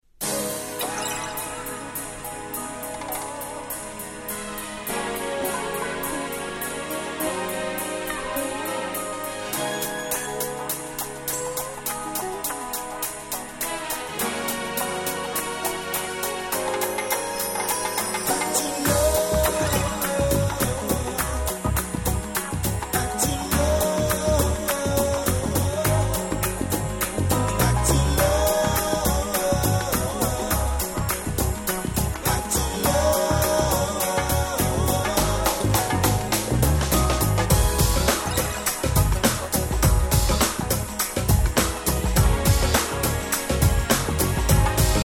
Nice UK R&B !!!